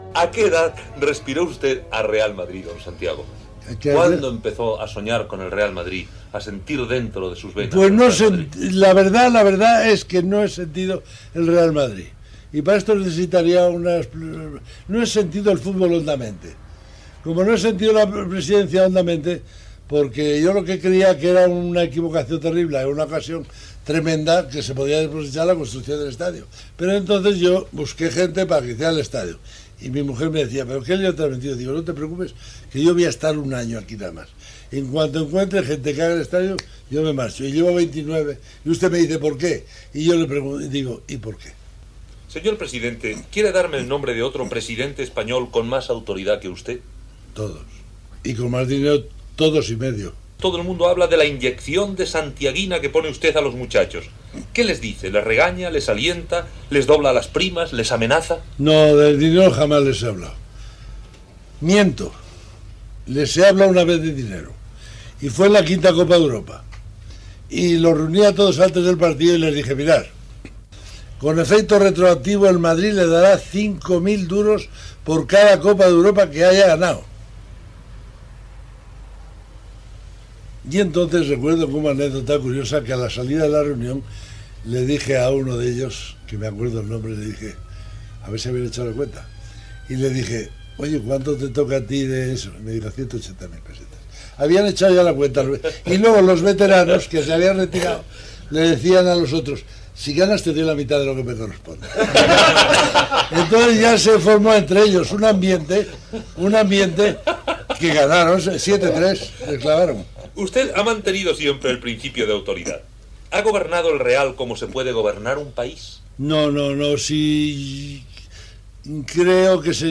Fragment d'un entrevista al president del Real Madrid Santiago Bernabeu